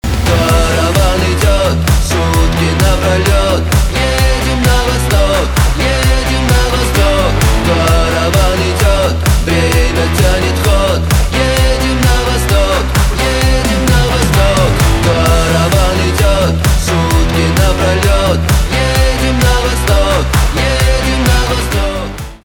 русский рок , гитара , барабаны
восточные , хлопки